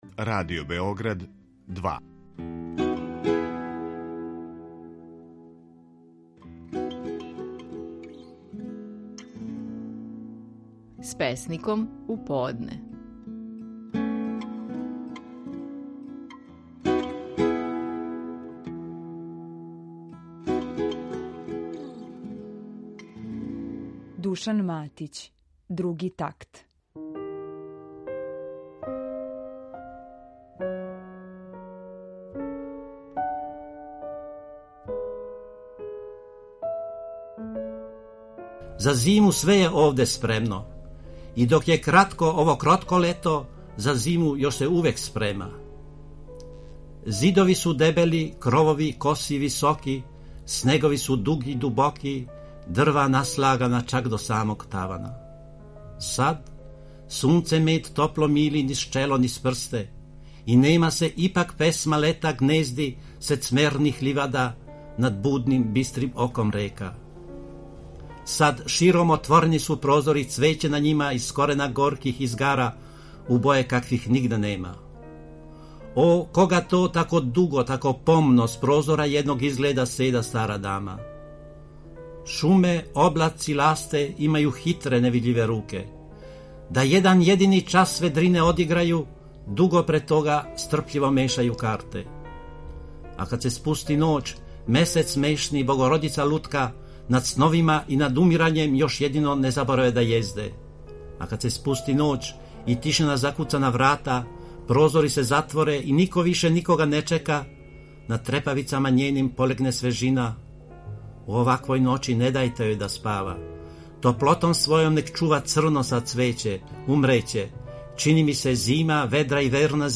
Стихови наших најпознатијих песника, у интерпретацији аутора.
Слушамо Душана Матића и песму „Други такт".